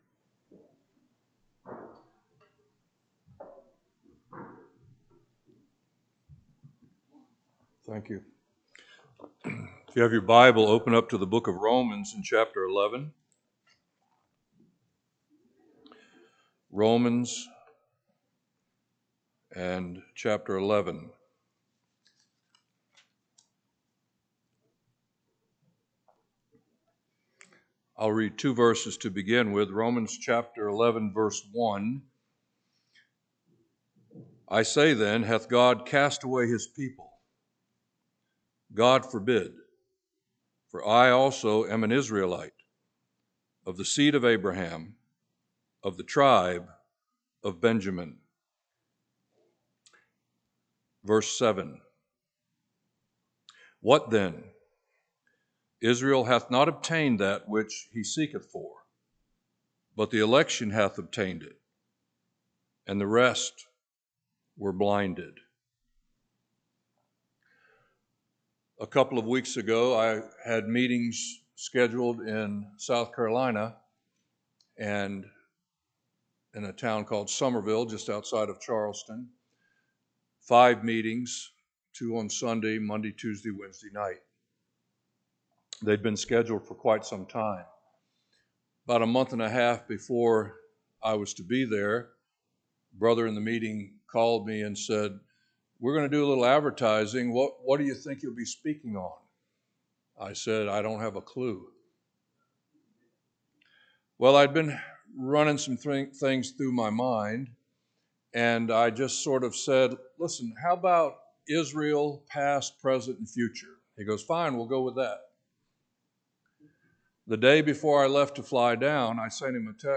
This is a time of teaching for the whole family, it involves teaching directly from the bible from local and national speakers, done in such a way as to draw in the young, old, and hesitant.